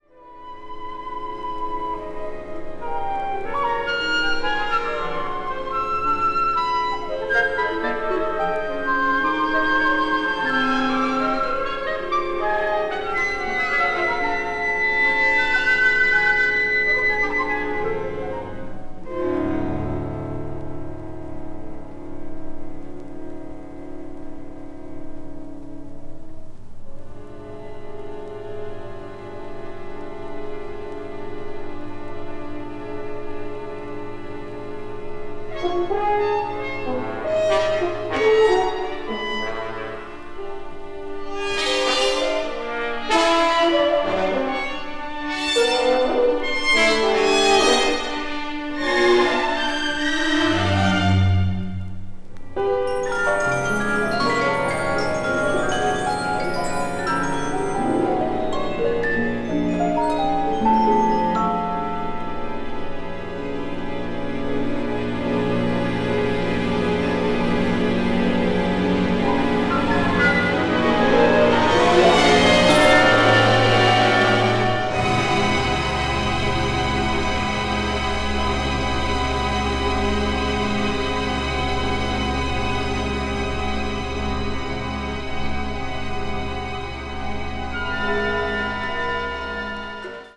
for Soprano and Orchestra